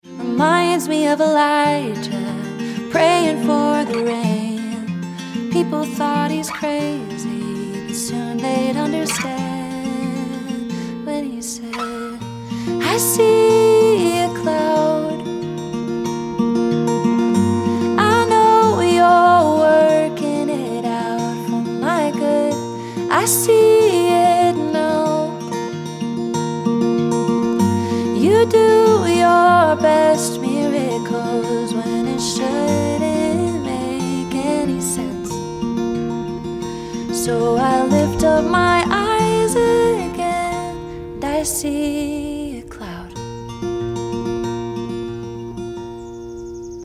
Live Acoustic Vid Of Cloud Sound Effects Free Download